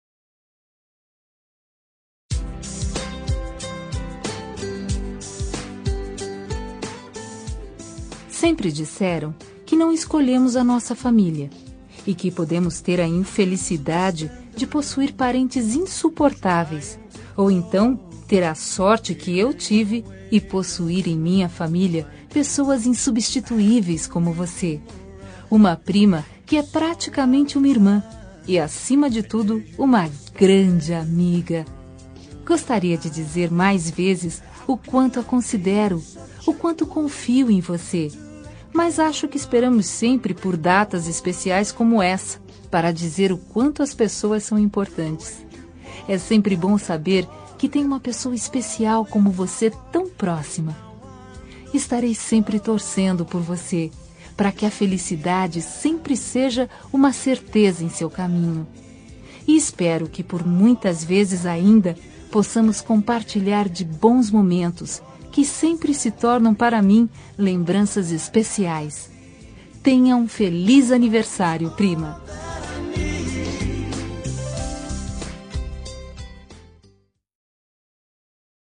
Aniversário de Prima – Voz Feminina – Cód: 042811 – Distante